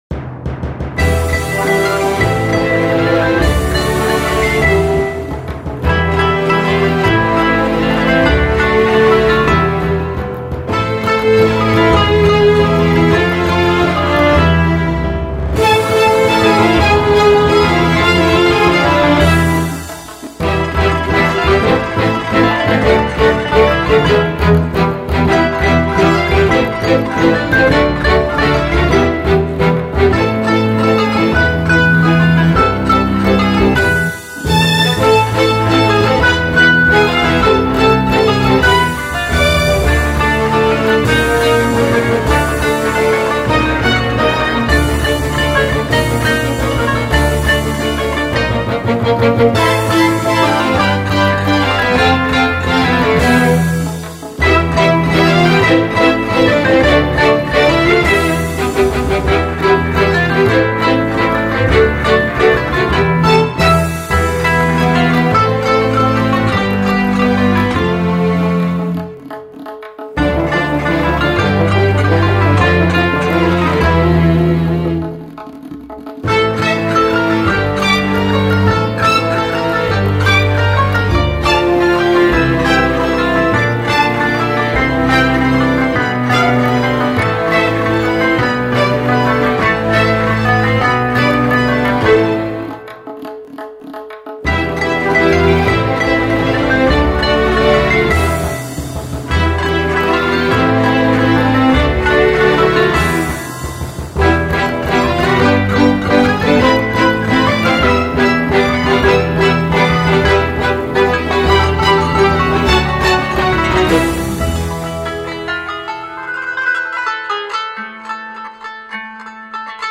قطعه‌ای ارکسترال
تکنوازی ساز قانون
ویلن و ویولا
ویلنسل
کنترباس
پیانو
هورن
فاگوت
تیمپانی
دُهل
تنبک